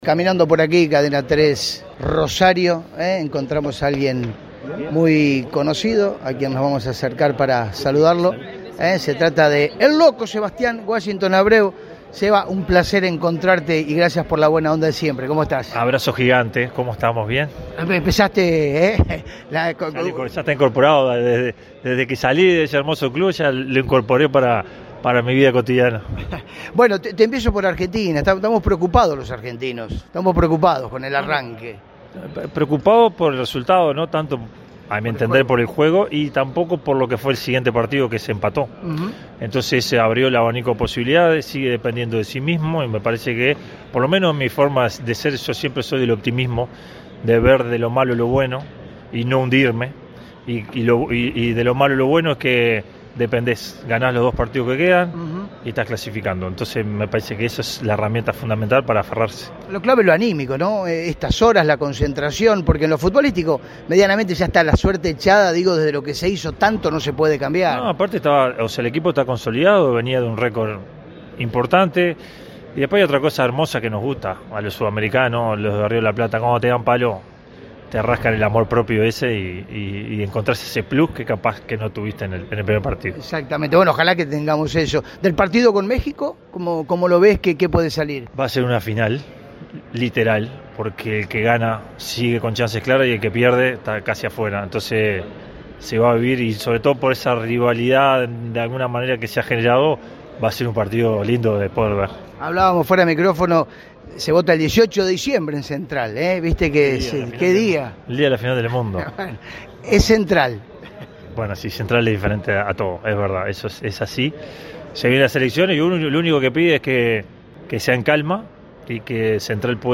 “En Central siempre estoy, solamente que en algún momento llegaré físicamente”, finalizó Sebastián Abreu desde Qatar.